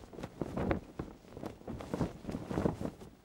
cloth_sail7.L.wav